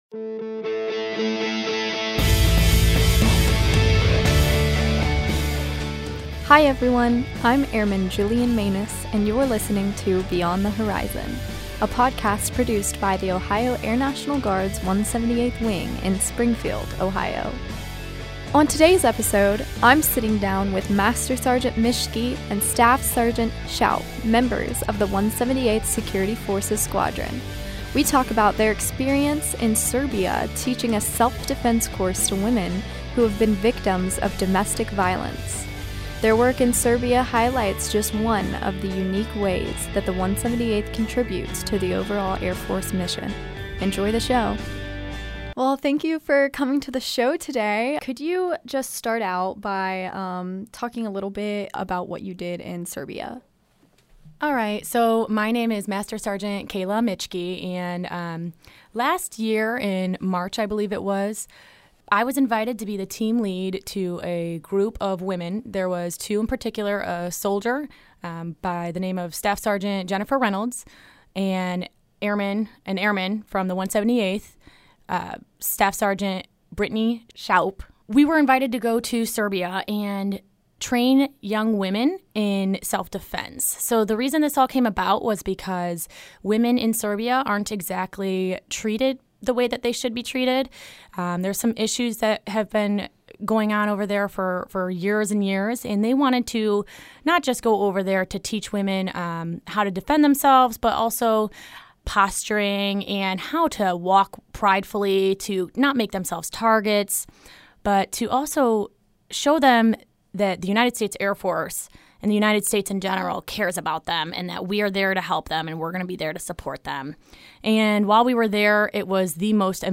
Ep. 11 - A conversation on women's self-defense in Serbia